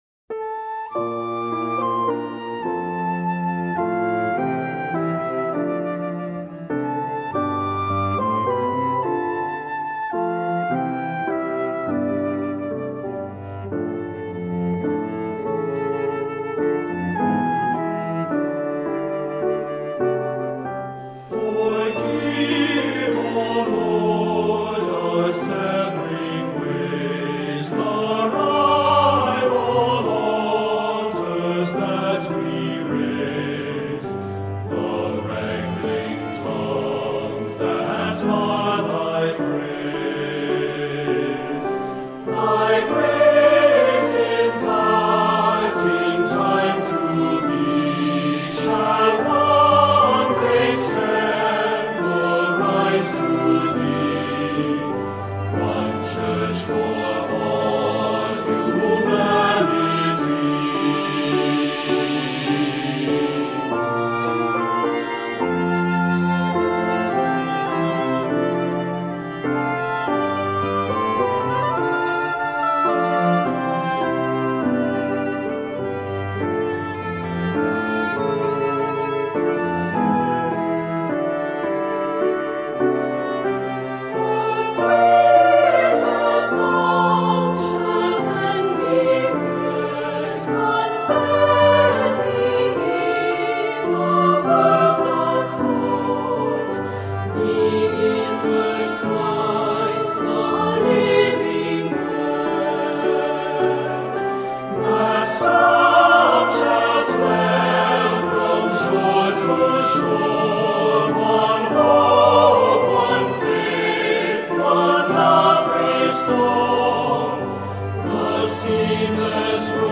Voicing: "Unison", "Descant"